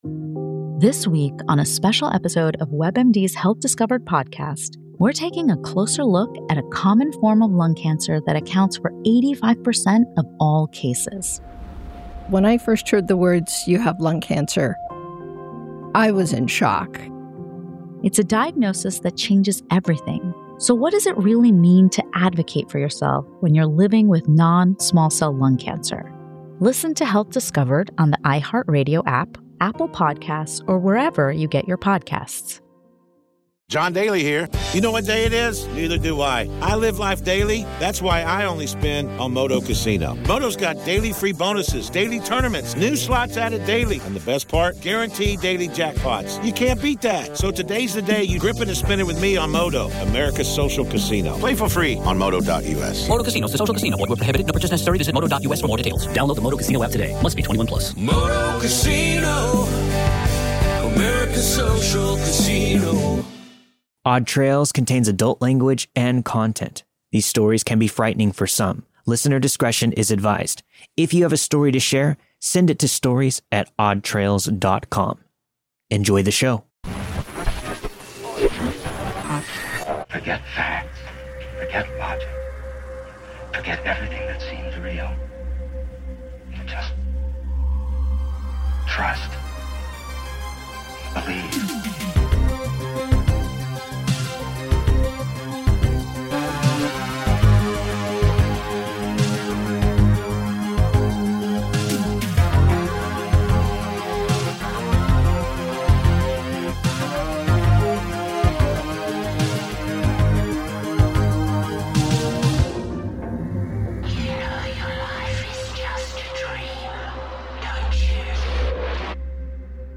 All the stories you've heard this week were narrated and produced with the permission of their respective authors.